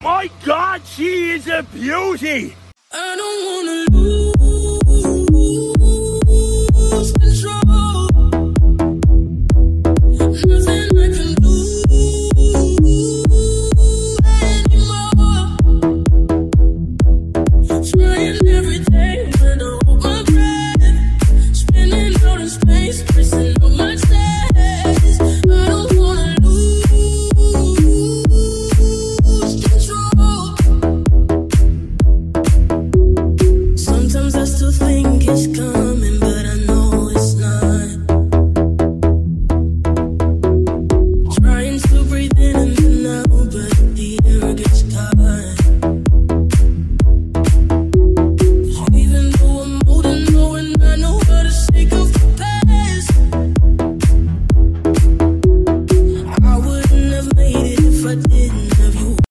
Chaser in the workshop this week - down pipe & screamer - boost pipes